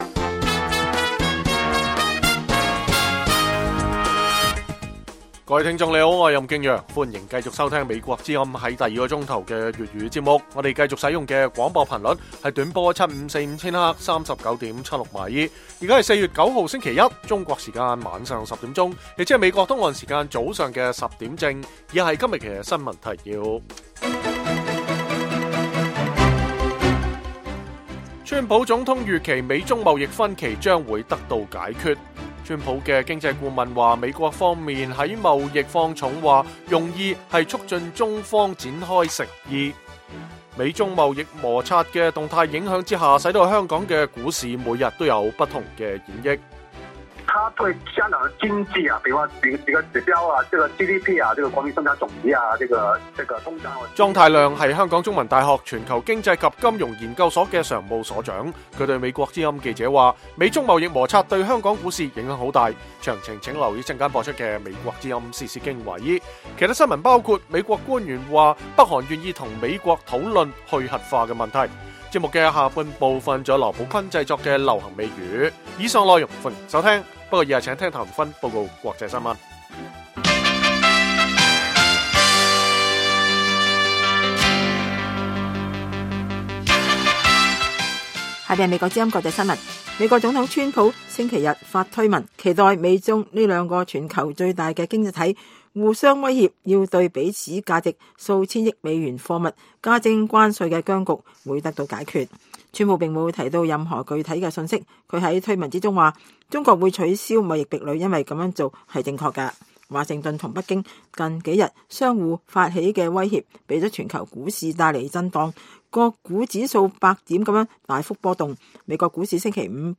粵語新聞 晚上10-11點
北京時間每晚10－11點 (1400-1500 UTC)粵語廣播節目。內容包括國際新聞、時事經緯、英語教學和社論。